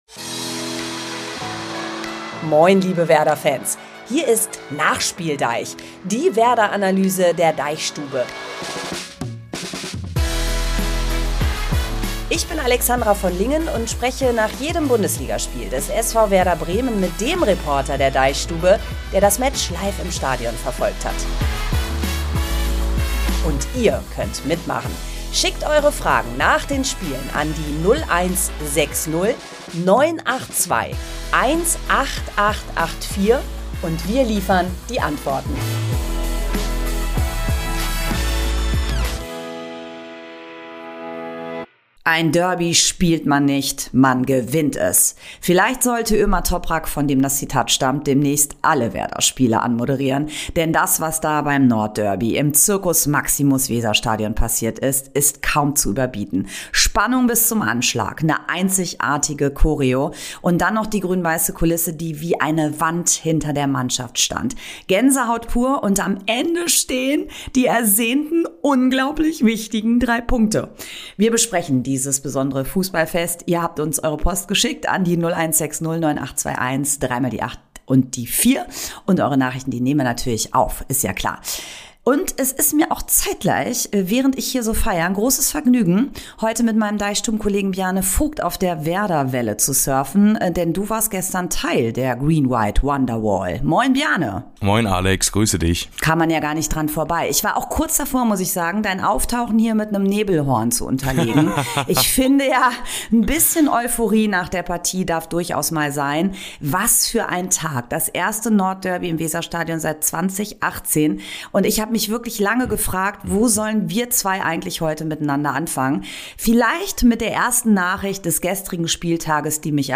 NachspielDEICH ist ein Fußball-Podcast der DeichStube.